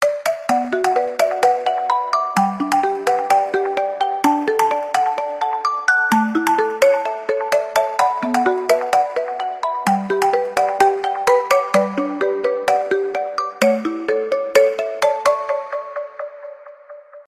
маримба , без слов